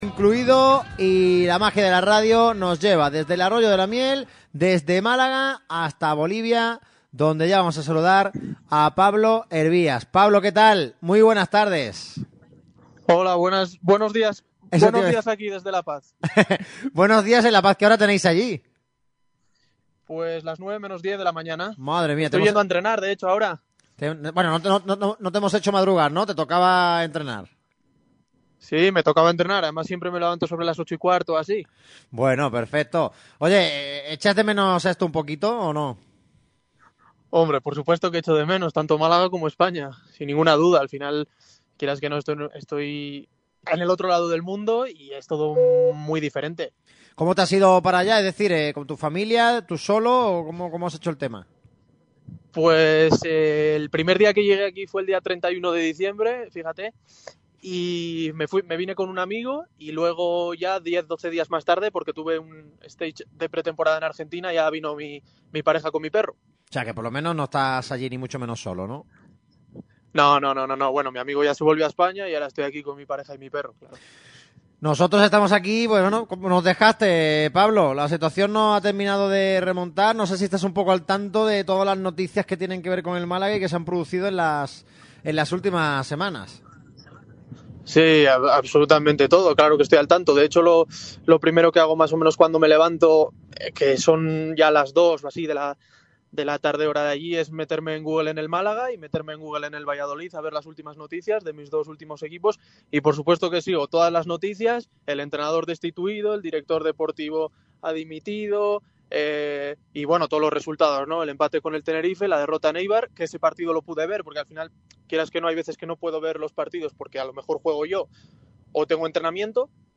Pablo Hervías pasó por Radio Marca Málaga antes del partido ante el Oviedo.